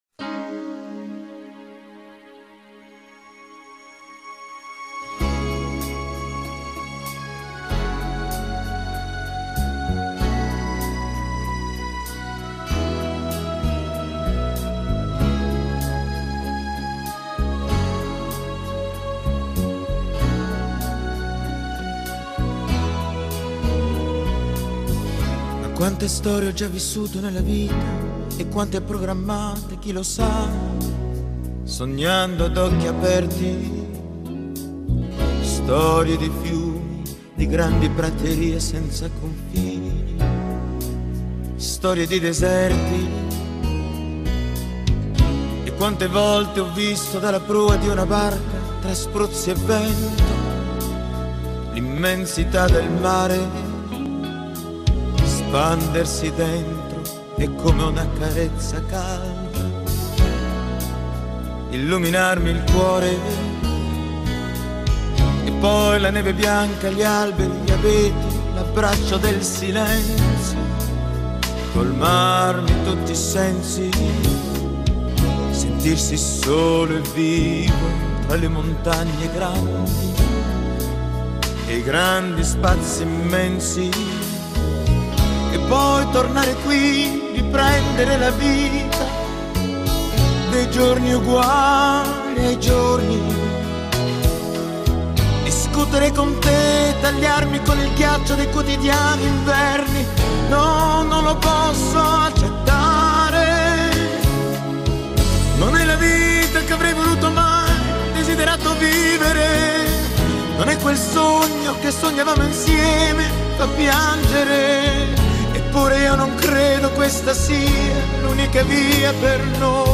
LATO 'B' DEL DISCO: VERSIONE STRUMENTALE